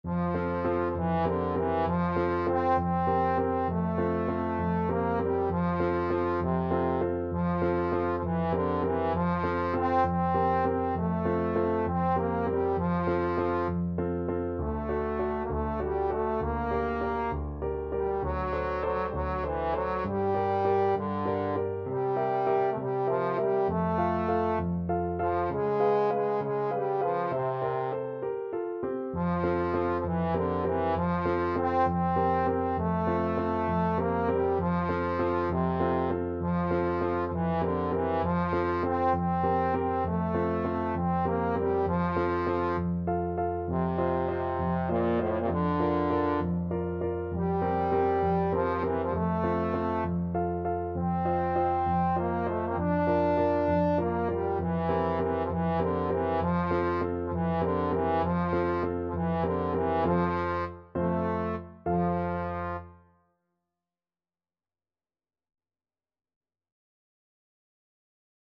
6/8 (View more 6/8 Music)
A3-D5
Classical (View more Classical Trombone Music)